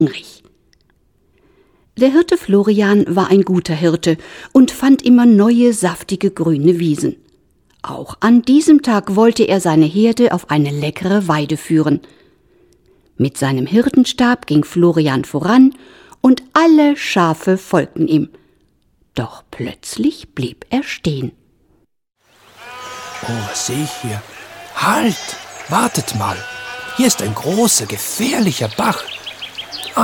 Lieder, Geschichten und Minimusical für Kinder
Kinderlieder